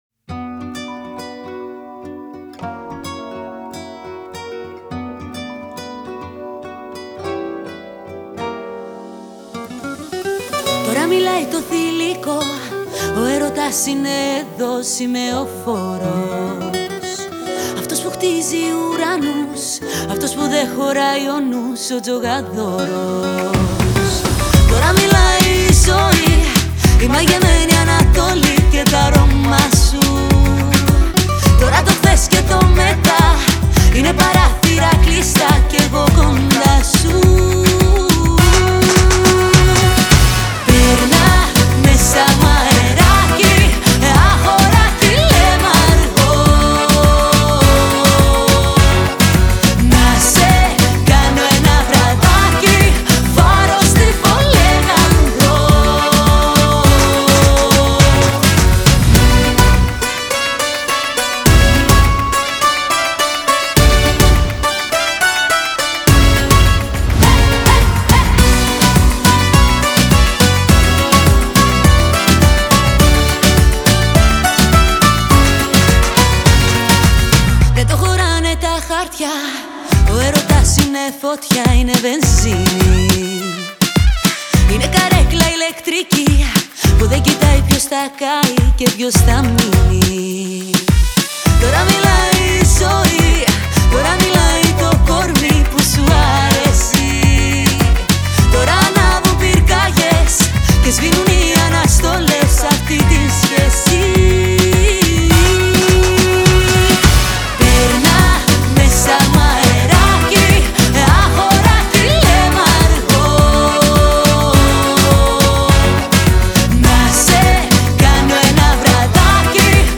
энергичная поп-песня
харизматичным исполнением и мощным голосом